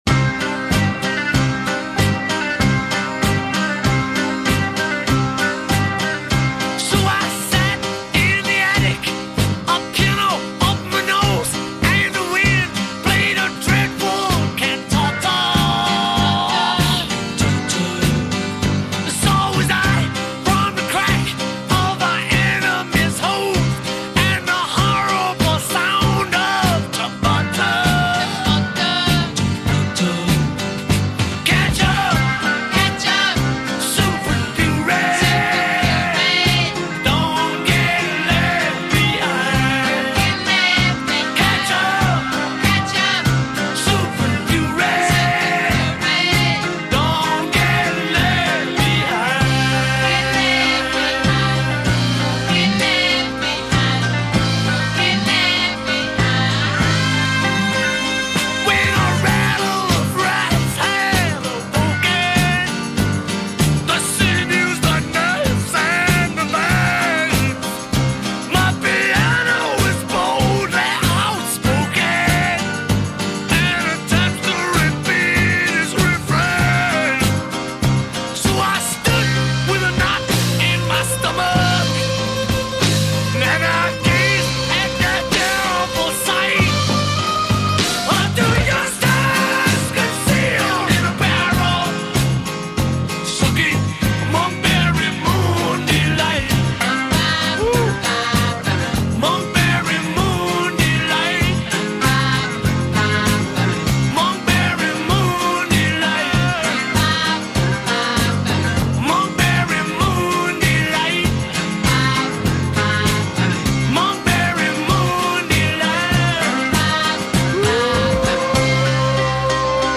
записанный в январе-марте 1971 года в RCA Studios, Нью-Йорк